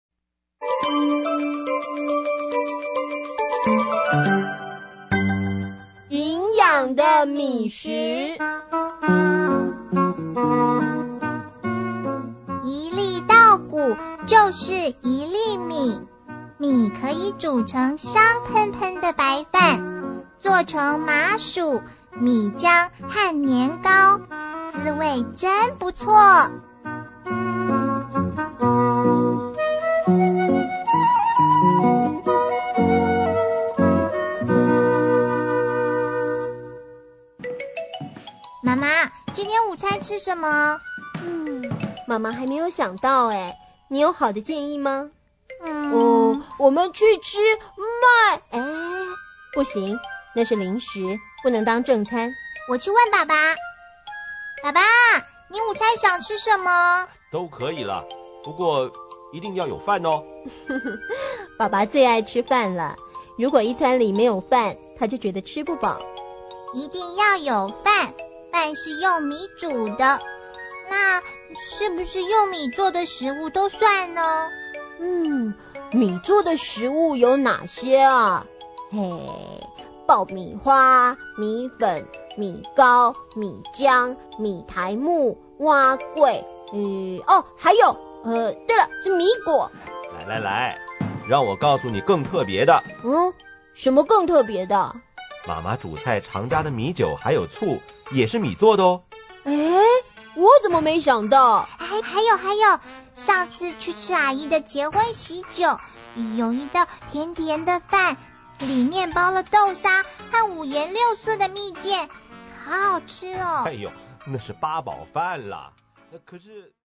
CD 每單元以「廣播劇」形式錄製，生動活潑又好聽